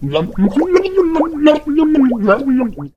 otis_start_vo_02.ogg